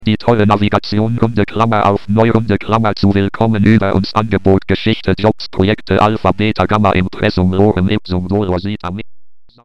Das heißt, dass sich die alte Navigation vorgelesen so anhört, während die Neue
Es zeigt sich: Die Navigation nach der neuen Methode wird vom Screenreader (in diesem Falle in der Kombination Jaws/Internet Explorer/Windows 2000) komplett vorgelesen, während beim alten Menü die Unterpunkte einfach verschluckt werden.